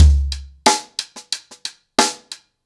Wireless-90BPM.9.wav